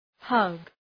Προφορά
{hʌg}